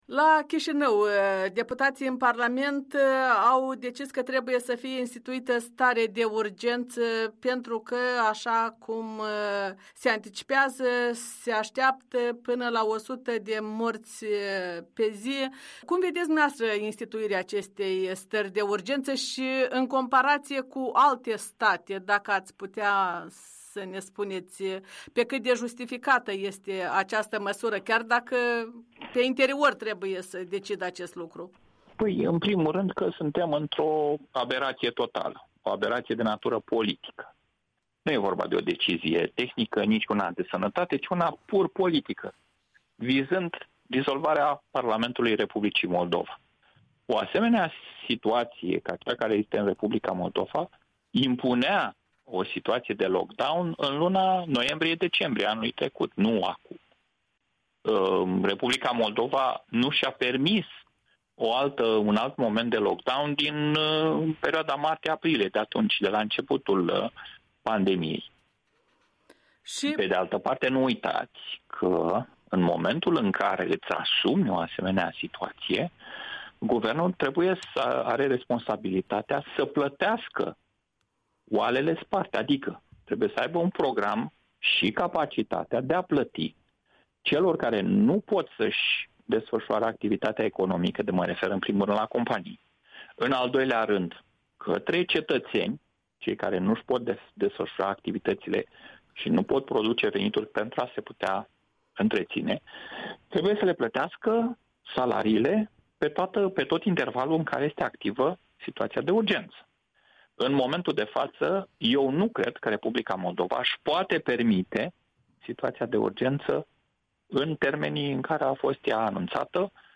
Interviu cu un analist politic de la București, fost consilier prezidențial pe probleme de securitate internațională.